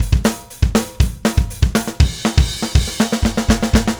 Power Pop Punk Drums Intro Fill.wav